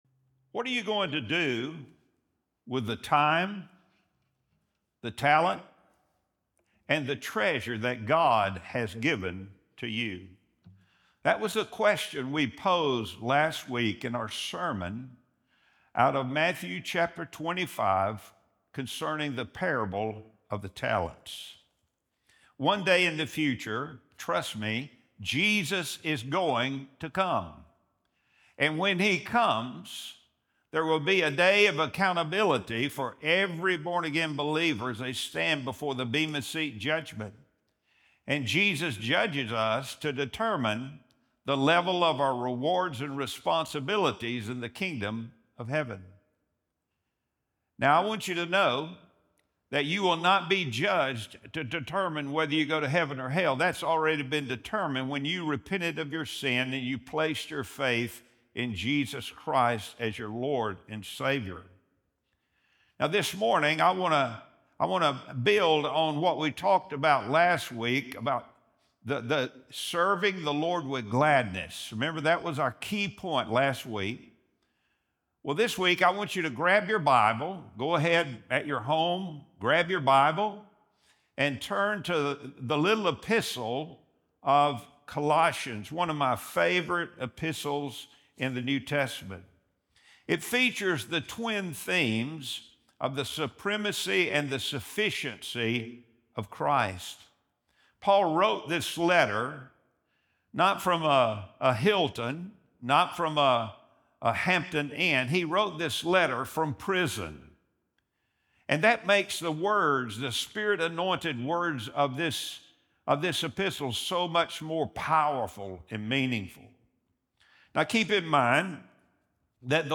Sunday Sermon | January 25, 2026